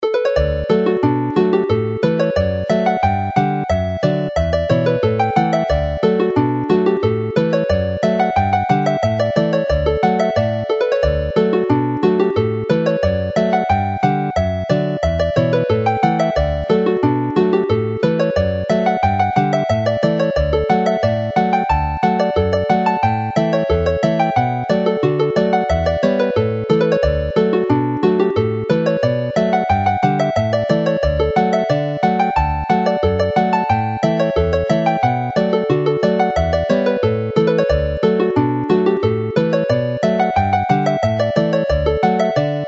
The Mill Street Hornpipe as a reel